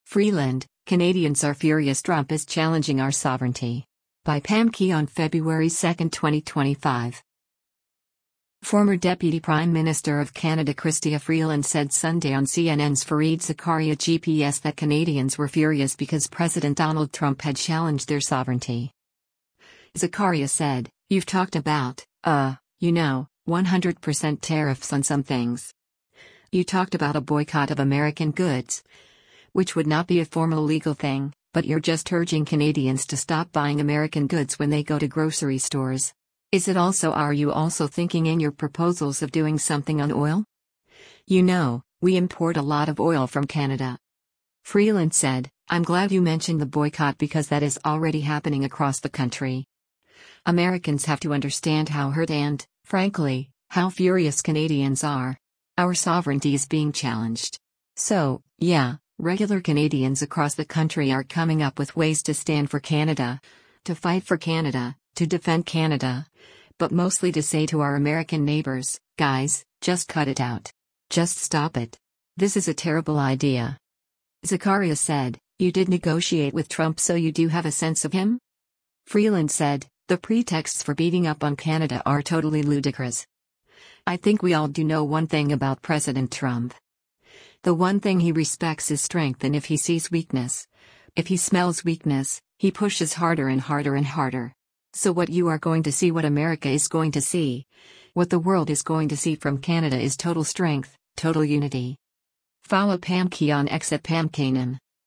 Former Deputy Prime Minister of Canada Chrystia Freeland said Sunday on CNN’s “Fareed Zakaria GPS” that Canadians were “furious” because President Donald Trump had challenged their sovereignty.